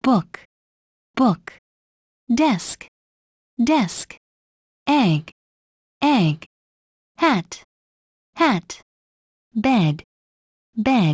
発音の練習をするために、ネイティブスピーカー（に近い）の音声を利用した音声ファイルの作り方です。